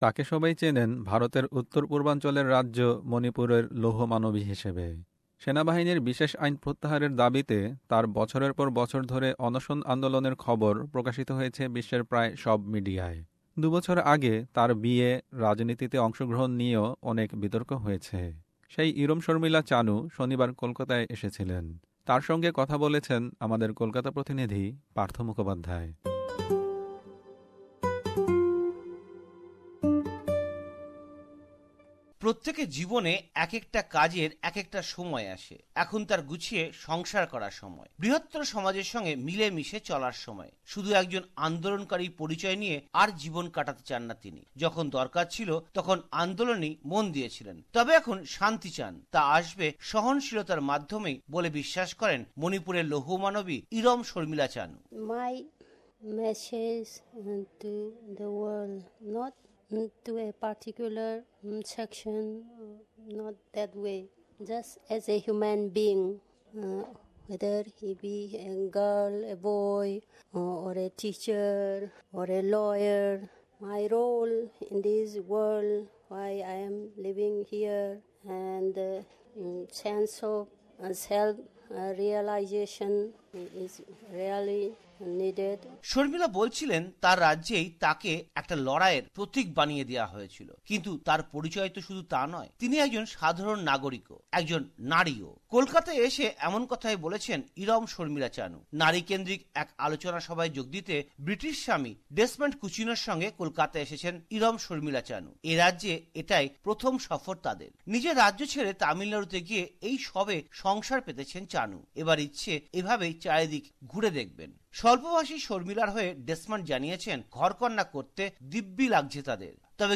Interview with Irom Sharmila Chanu, civil rights activist